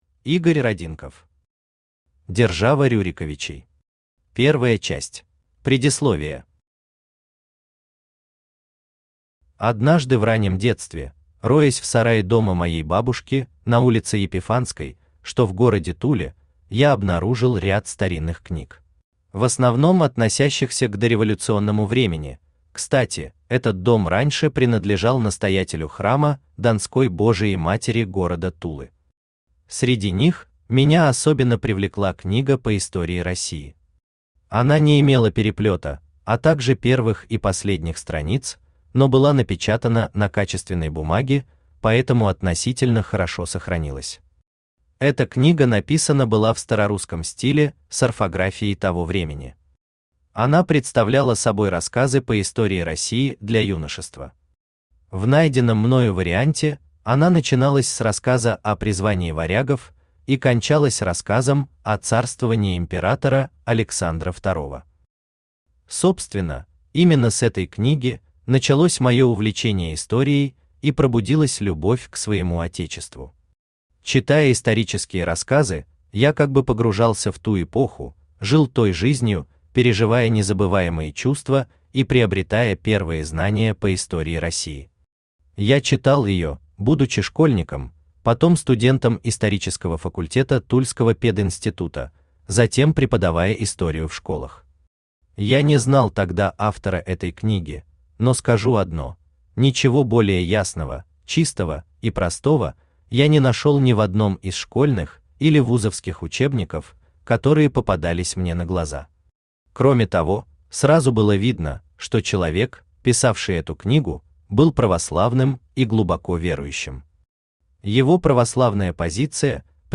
Аудиокнига Держава Рюриковичей. Первая часть | Библиотека аудиокниг